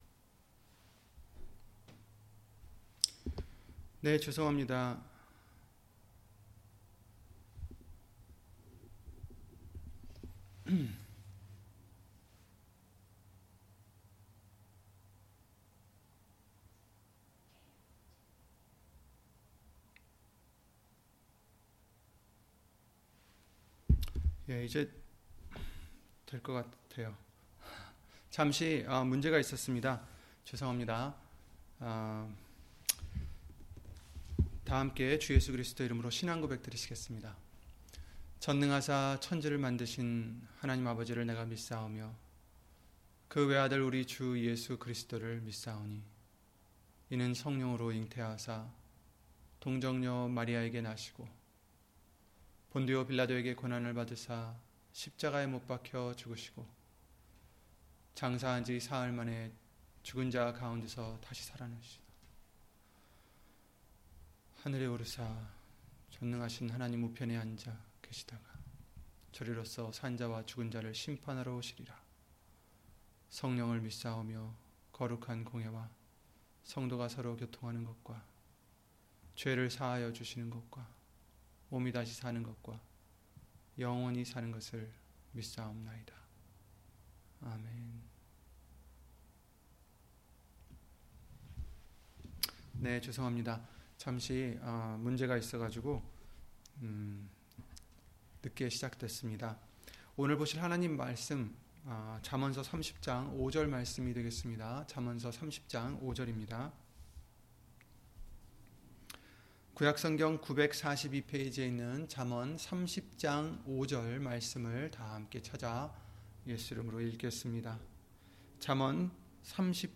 잠언 30장 5절 [성서 주일] - 주일/수요예배 설교 - 주 예수 그리스도 이름 예배당